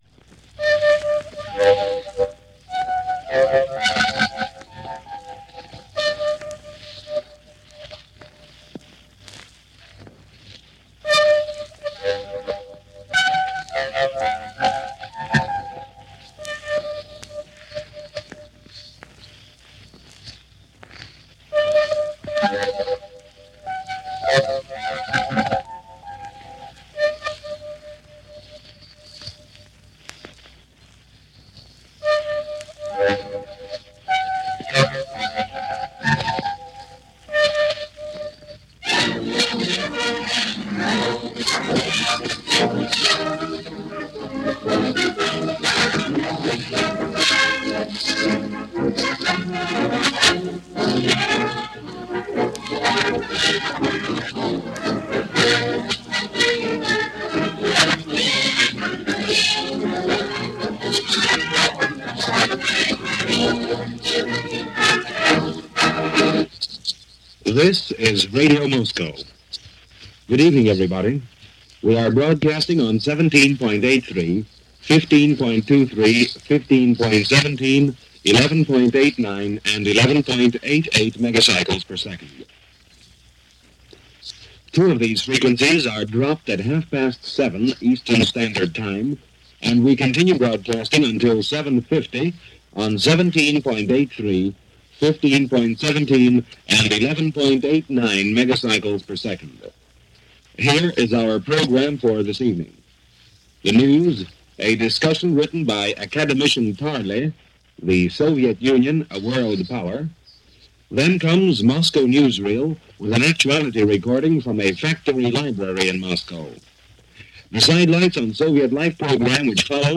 October 7, 1947 – Radio Moscow in one of their daily broadcasts to English speaking listeners all over the world.
The broadcasts are faint and noisy – and to some, offer a crystal clear memory of twisting dials and listening for familiar sounds; the beeps, the sign-ons and the theme music each broadcaster used.